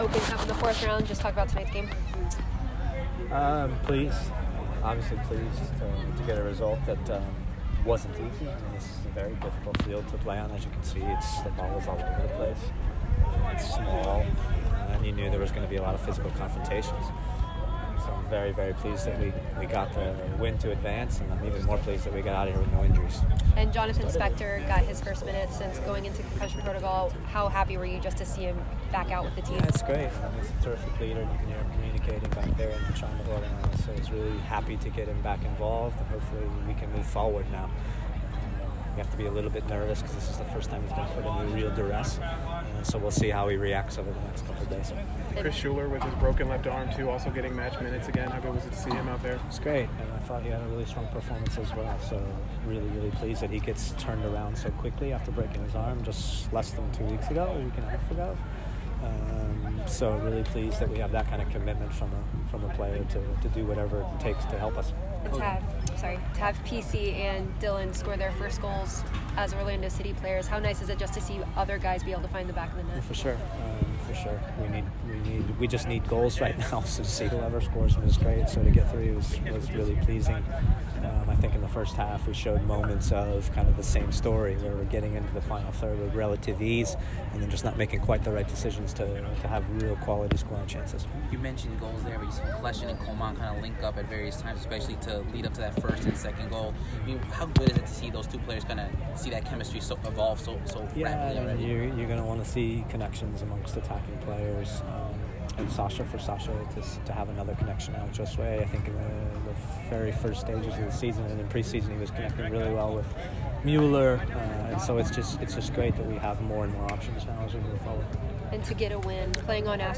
Orlando City SC coach Jason Kreis speaks to the media after win over Miami United.